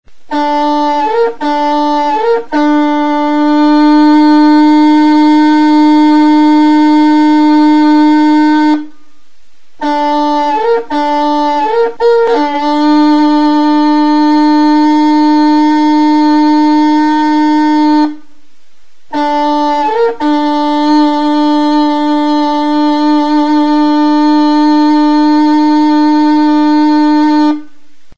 Enregistré avec cet instrument de musique.
TRONPETA; TUTUBIA
Aérophones -> Anches -> Double (hautbois)
Gaztainondo makila bati ateratako azal zinta batekin egindako oboea da.
Ez du digitaziorako zulorik eta nota bakarra ematen du.